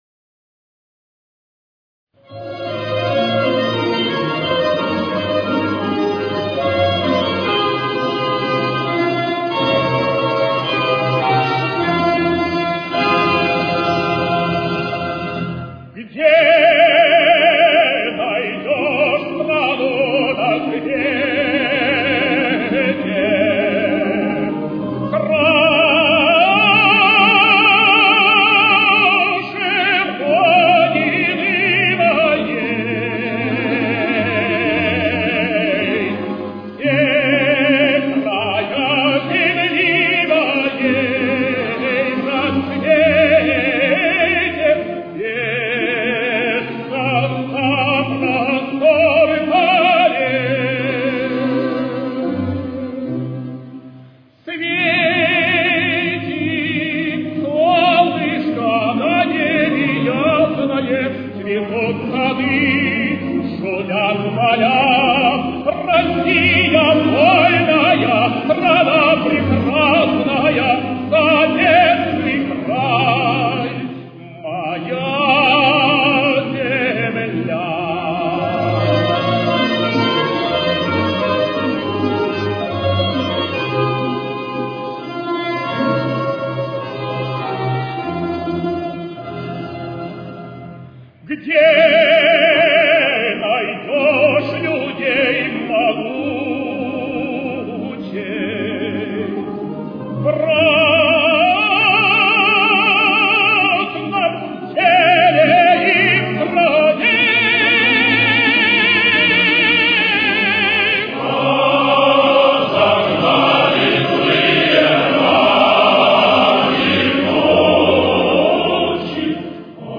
Ля-бемоль мажор.